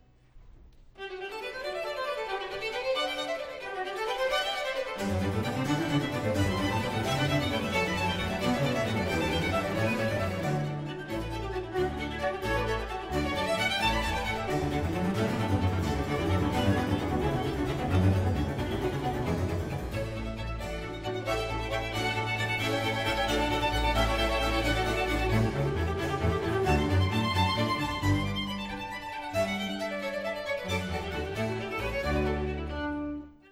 Written for three choirs of strings (violin, viola, cello) and continuo, the Brandenburg Concerto No. 3 explores in depth the surprising possibilities inherent in groups of similar-sounding instruments. One of the critical ingredients is uniform virtuosity; the nine string parts are essentially equal, and each player comes forward at least briefly as a soloist. Subtle variations in texture and tone color are also extremely important.